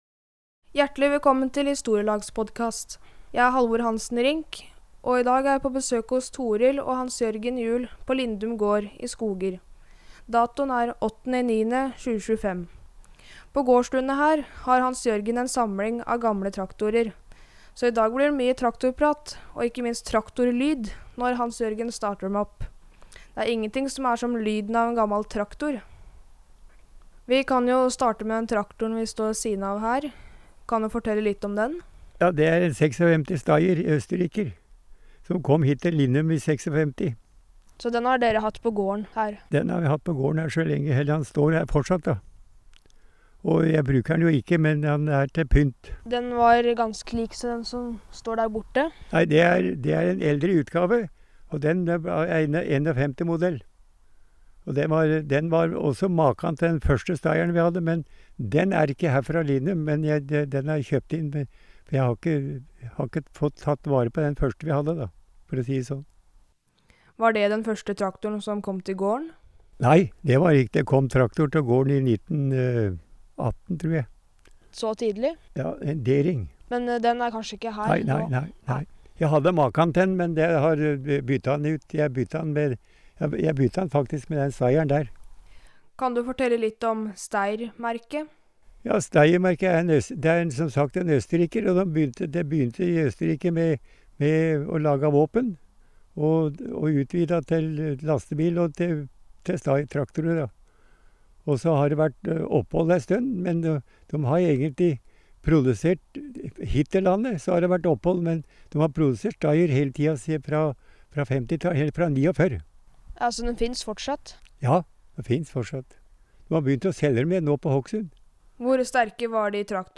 Ingenting er som lyden av en gammal traktor!
Vi får også høre når han starter dem opp. Klarer du å høre forskjell på en ensylindret totakter og en tosylindret firetakter Steyr-traktor?